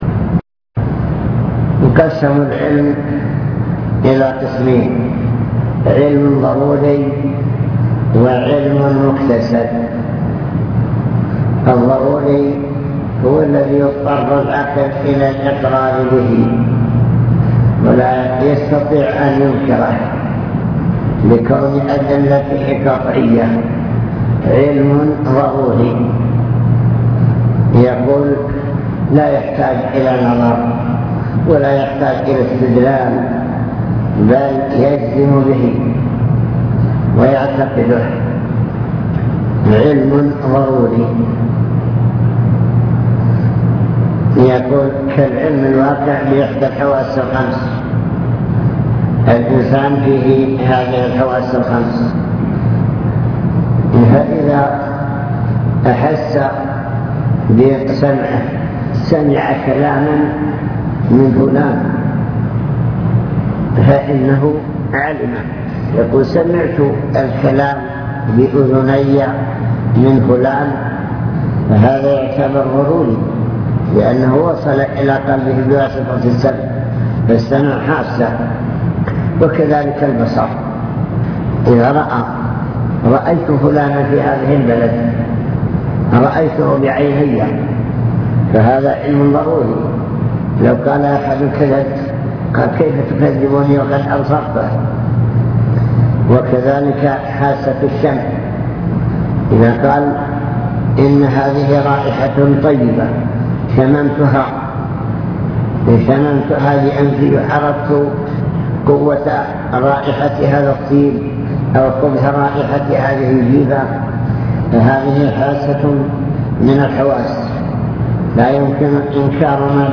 المكتبة الصوتية  تسجيلات - محاضرات ودروس  محاضرة في وادي ثرجوم